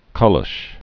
(kŭlŭsh) also ka·la·sha (-lŭsh-ə)